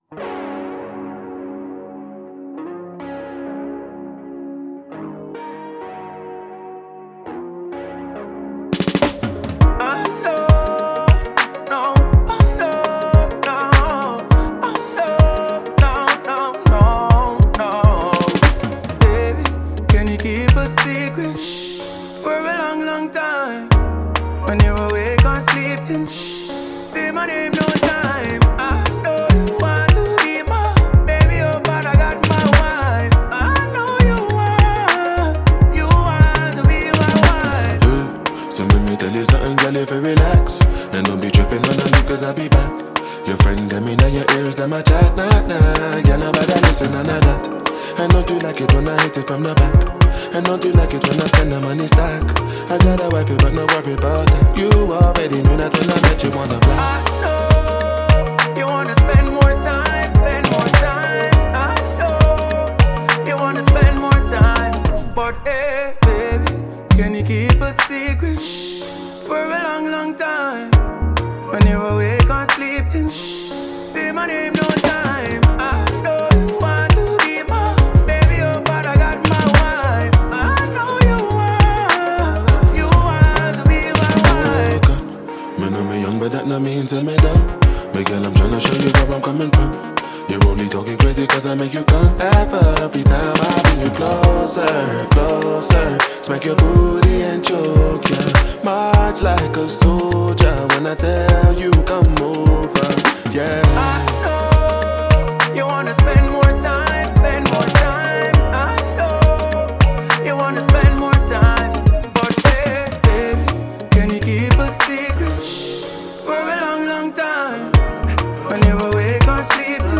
Released in 2019, it mixes Afrobeat, reggae, and hip-hop.